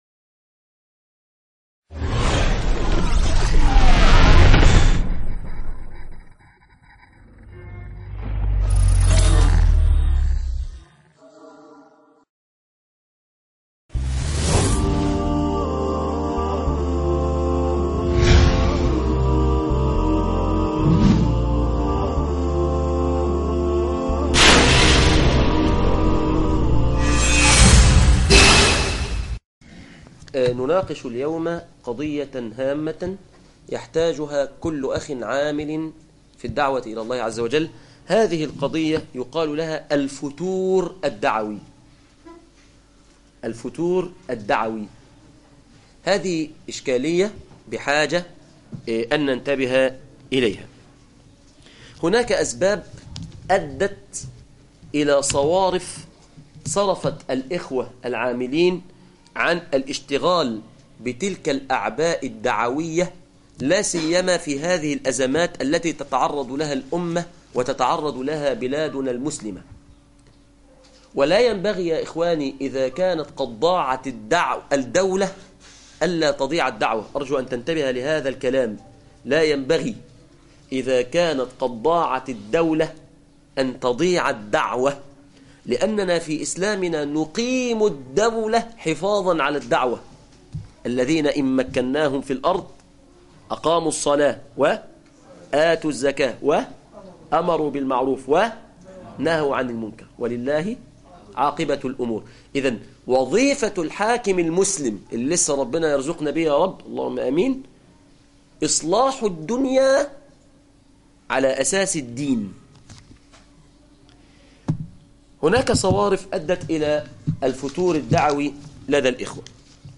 الفتور الدعوي عند الشباب - درس يوم الجمعة 23 يناير 2015